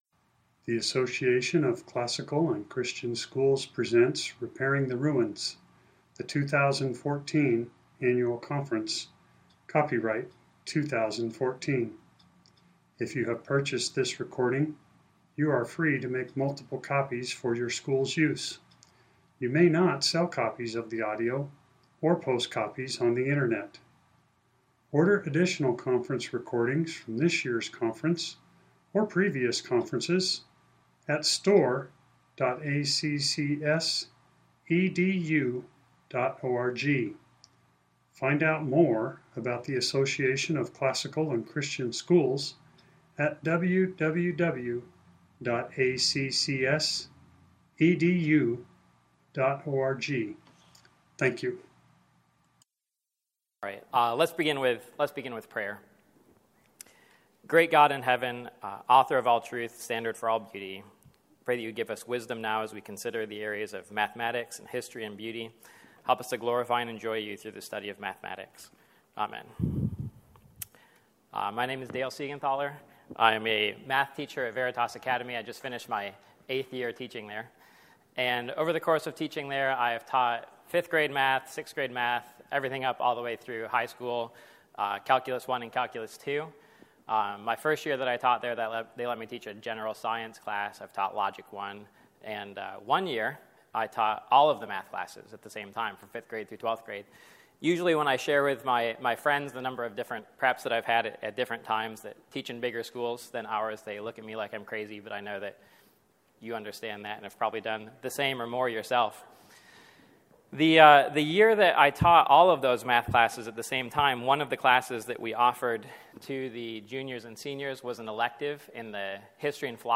2014 Workshop Talk | 1:03:24 | All Grade Levels, Math
The Association of Classical & Christian Schools presents Repairing the Ruins, the ACCS annual conference, copyright ACCS.